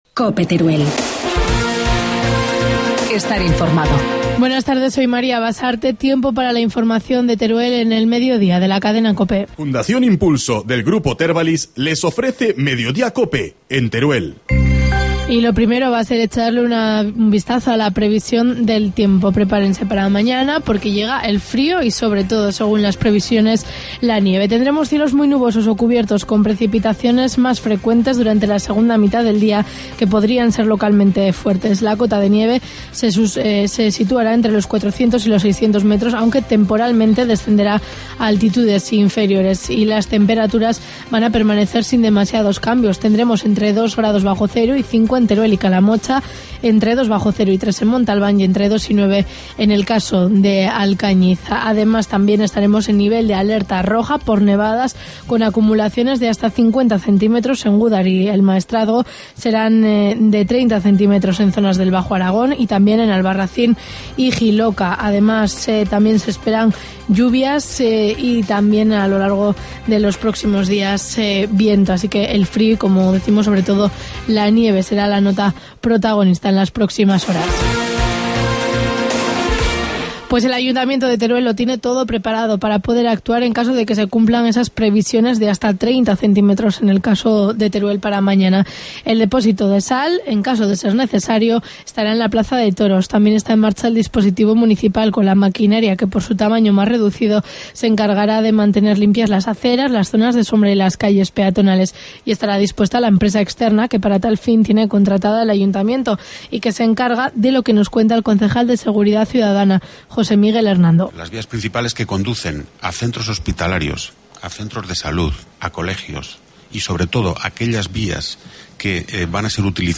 Informativo mediodía, miércoles 27 de febrero